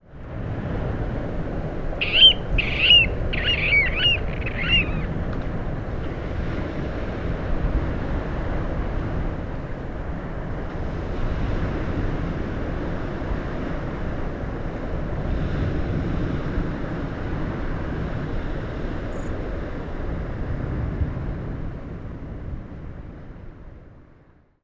birds made a clicking sound nearly all day, but rarely “cried” their long pig-like calls.
081226, Water Rail Rallus aquaticus, territorial call
wrail.mp3